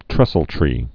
(trĕsəl-trē)